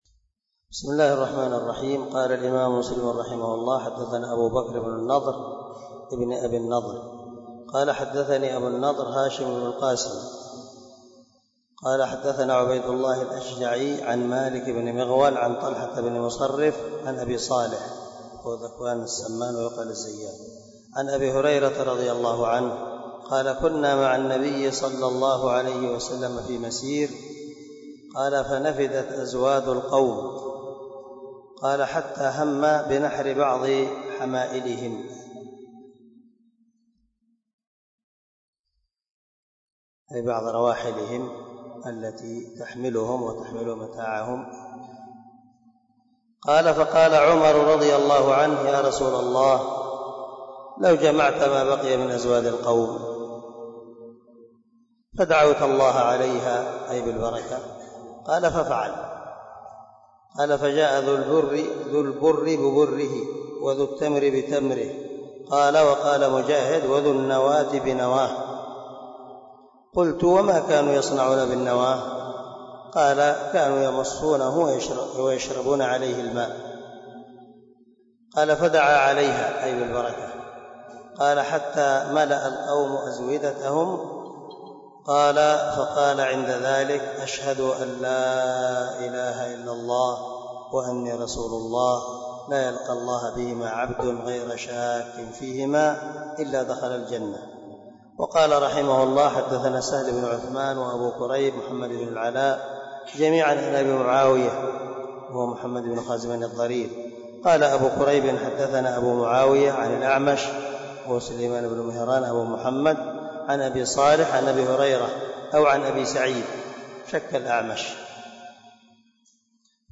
016الدرس 15 من شرح كتاب الإيمان حديث رقم ( 27 - 29 ) من صحيح مسلم